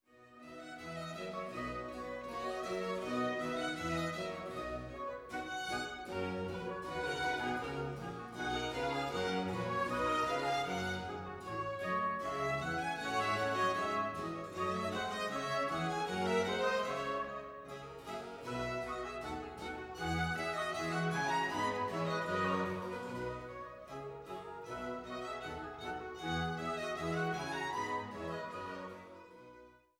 Duett (Sopran, Bass)